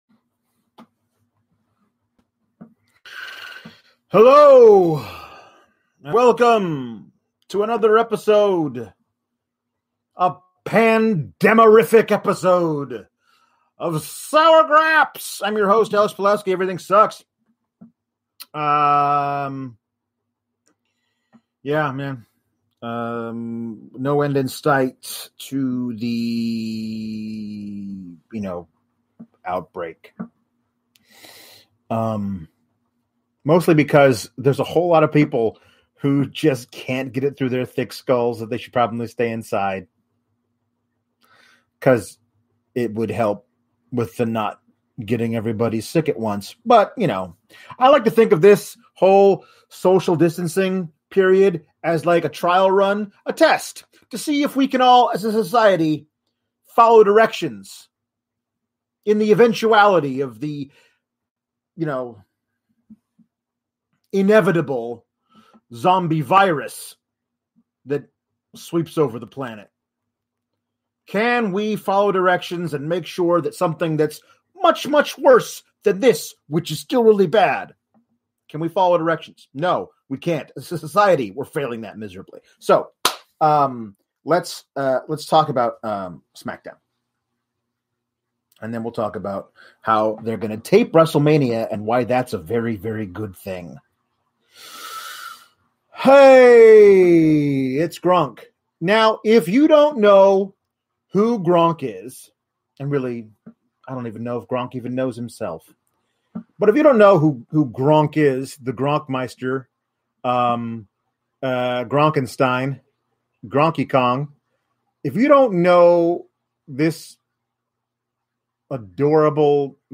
Would never have noticed the washer had you not said something.